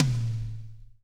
-DRY TOM 3-R.wav